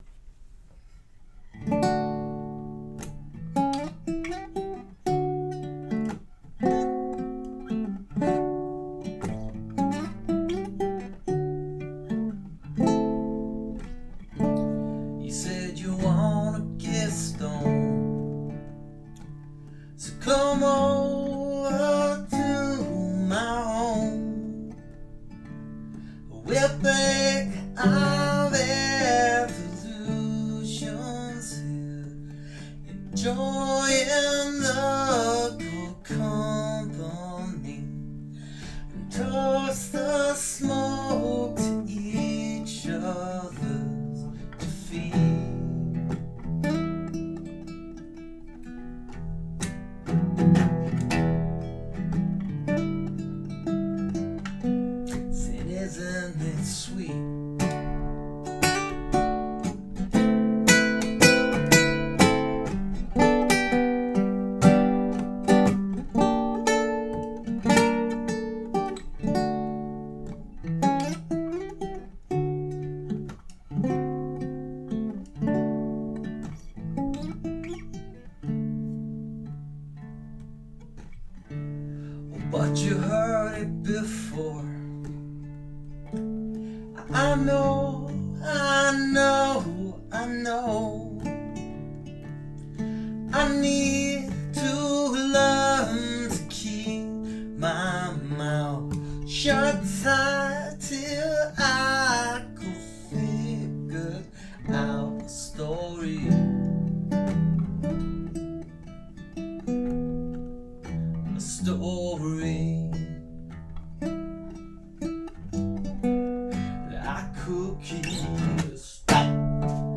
rock and/or roll
I like the guitar pretty well.